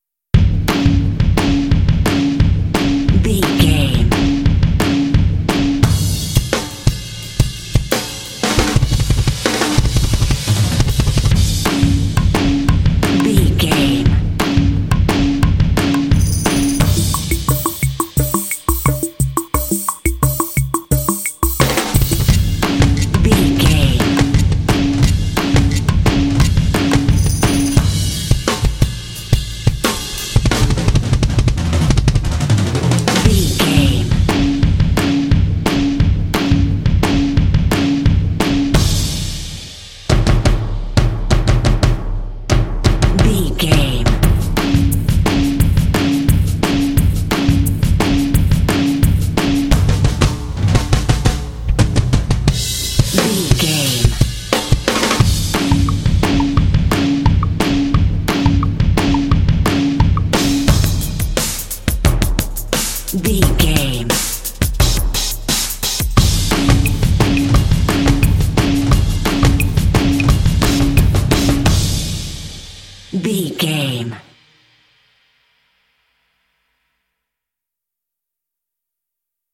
Epic / Action
Atonal
driving
intense
powerful
energetic
drums
percussion
rock
heavy metal